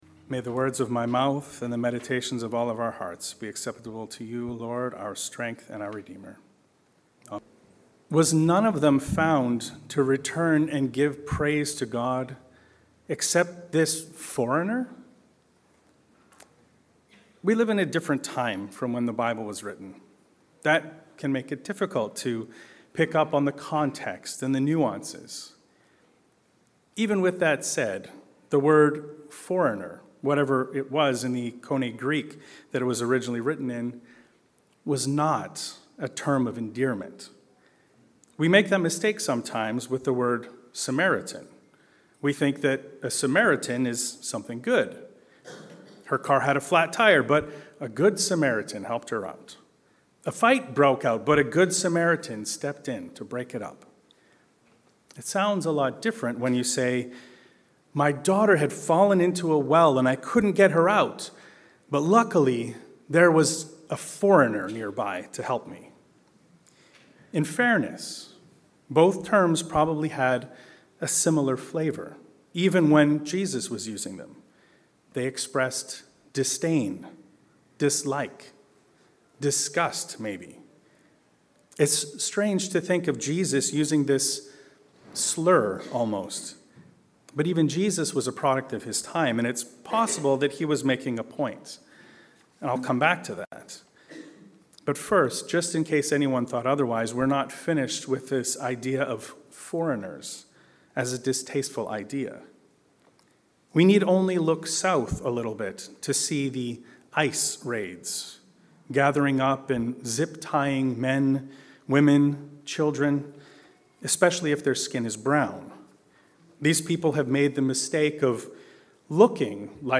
Thankful Foreigners. A sermon on Jeremiah 29 and Luke 17.11-19